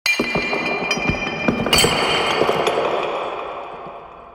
A segment of the "Glass Break 2" audio file, with a spatial filter added onto it. This sound is correlated with the letter "w" on the computer keyboard.